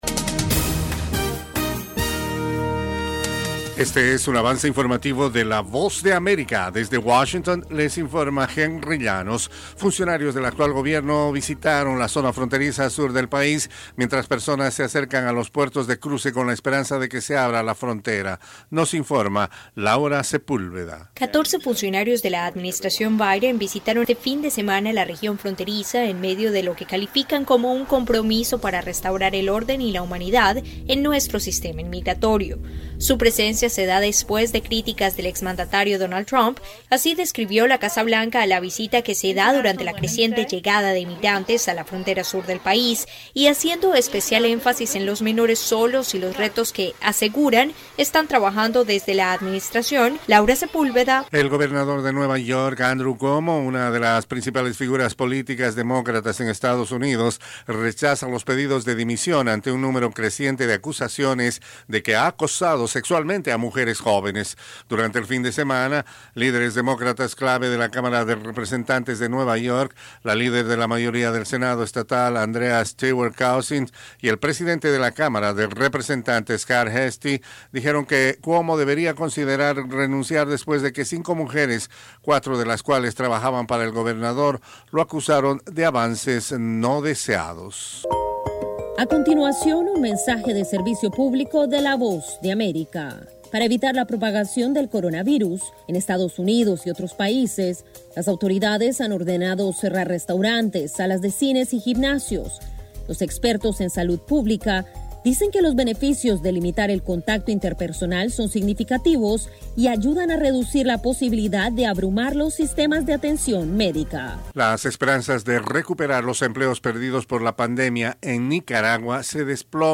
Cápsula informativa de tres minutos con el acontecer noticioso de Estados Unidos y el mundo.
Desde los estudios de la Voz de América en Washington informa